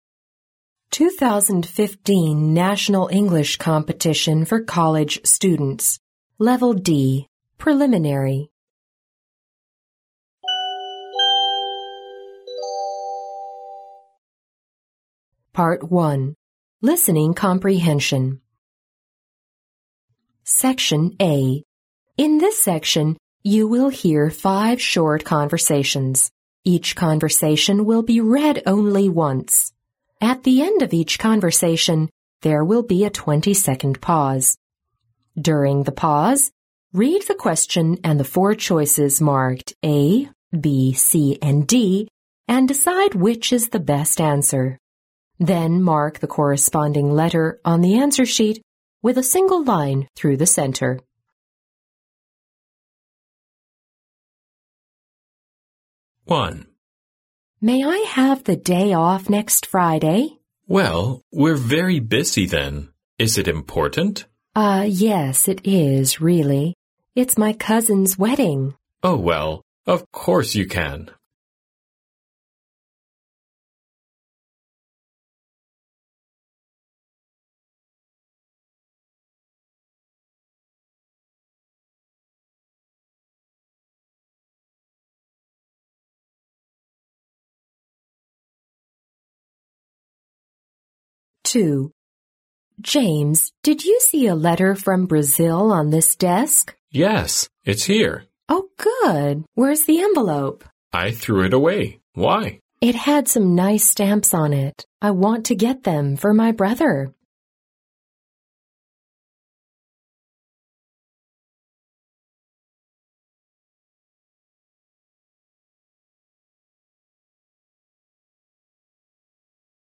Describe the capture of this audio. In this section, you will hear five short conversations. Each conversation will be read only once.